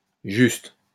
wymowa:
IPA[ʒyst]